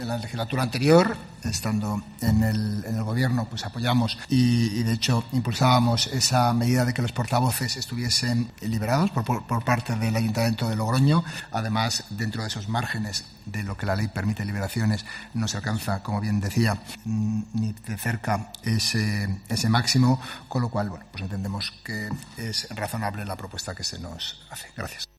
Por el PR, Rubén Antoñanzas explicaba que apoya la propuesta porque hay mucho trabajo en este Ayuntamiento.